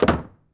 sounds_door_close_01.ogg